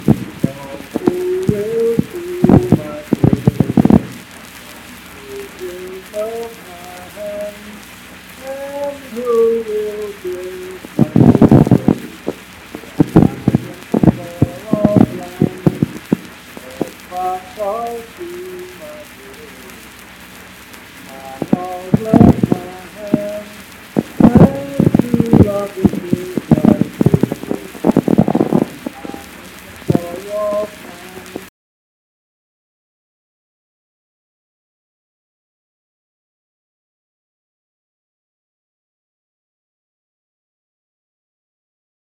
Unaccompanied vocal music performance
Voice (sung)
Randolph County (W. Va.)